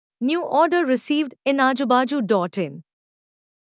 New_order_received_notification.mp3